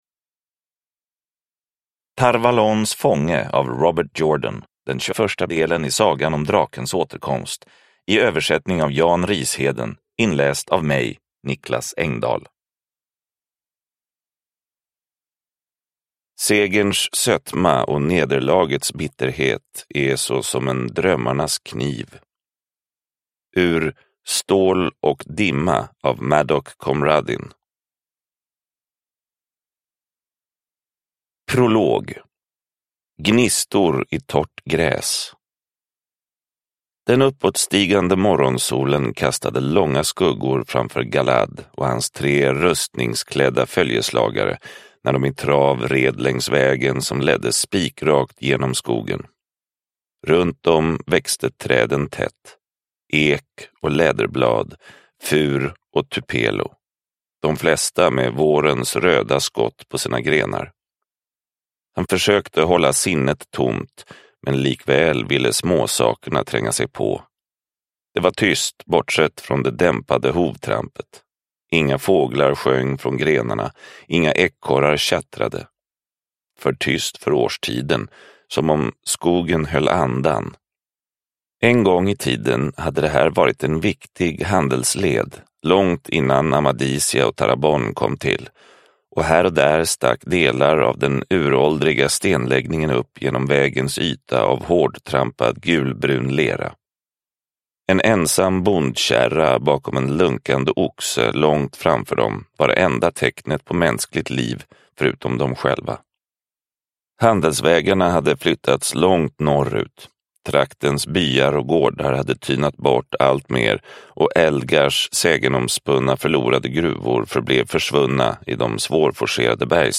Tar Valons fånge – Ljudbok – Laddas ner